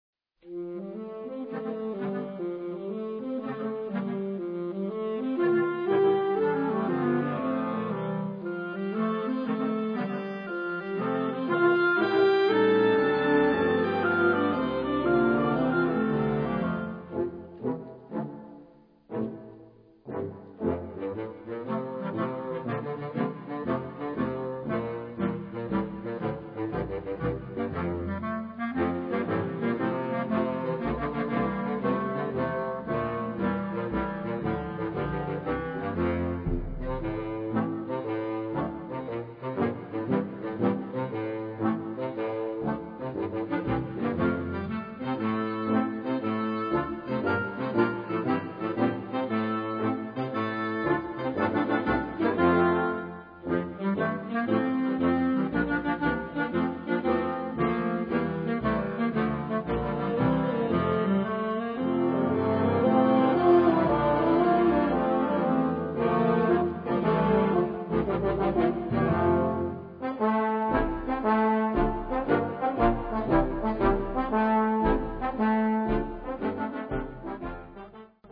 Gattung: Konzertmarsch
Besetzung: Blasorchester
whimsical rhythms